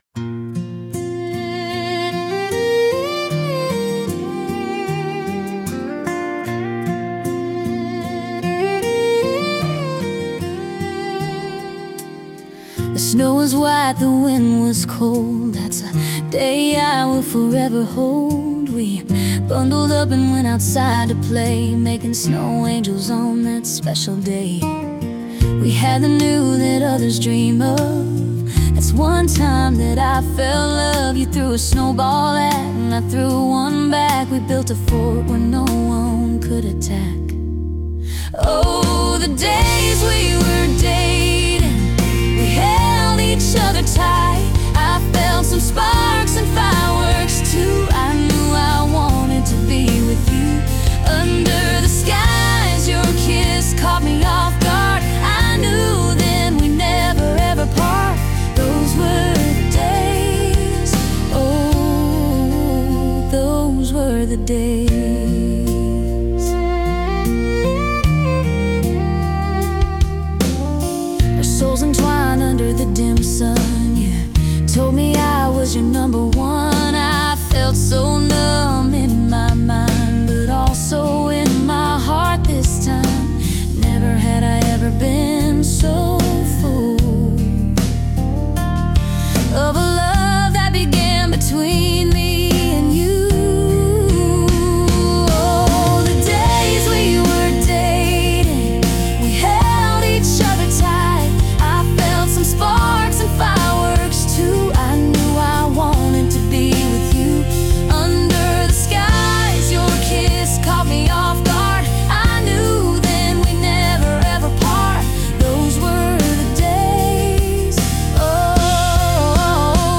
Category: Country